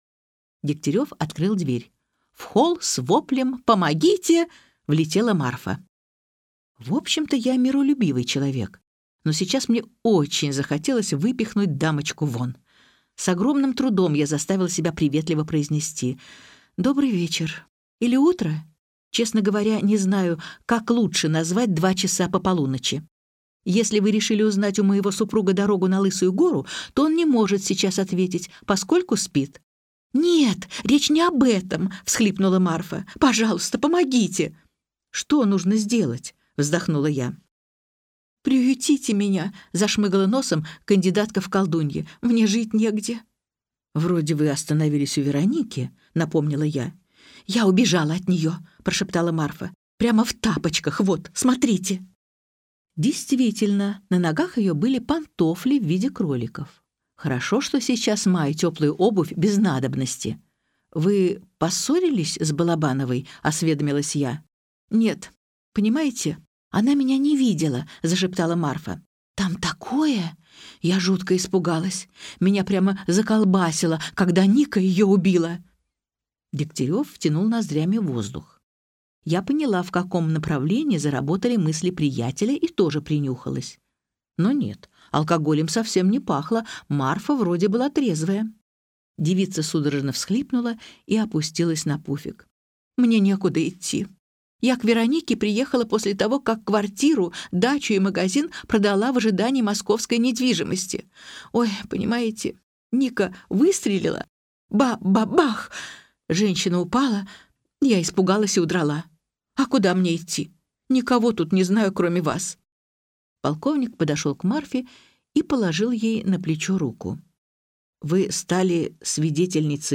Аудиокнига Ночной клуб на Лысой горе - купить, скачать и слушать онлайн | КнигоПоиск